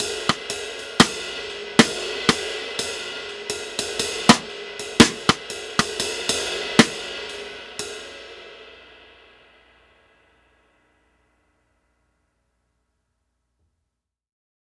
Unison Jazz - 4 - 120bpm - Tops.wav